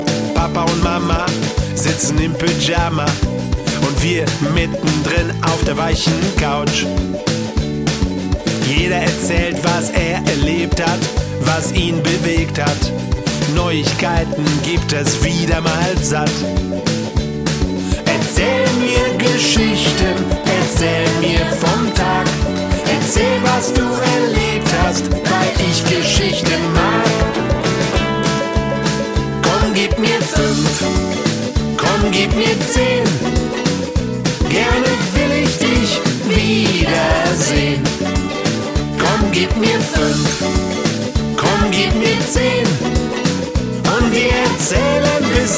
Mal swingend, mal rockig, mal Texte für den Kopf,
mal Rhythmen die in die Beine gehen...